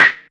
04PERC01  -R.wav